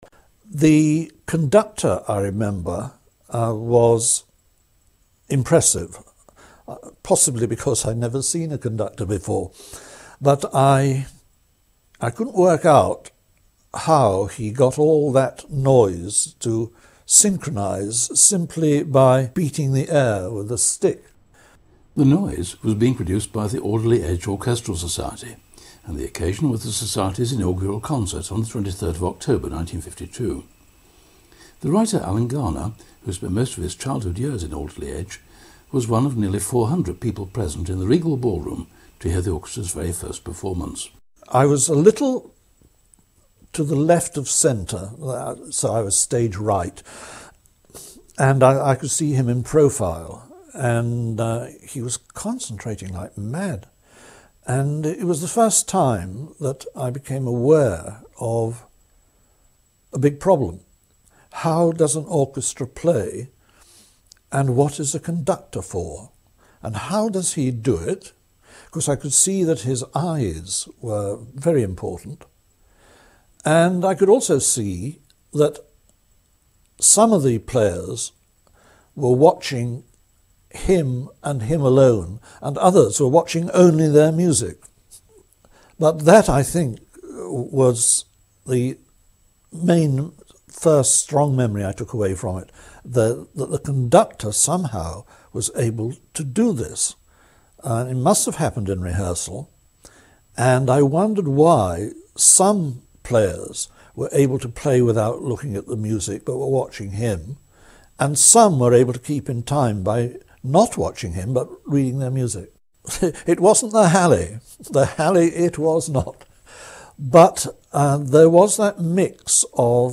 Alan Garner interview.mp3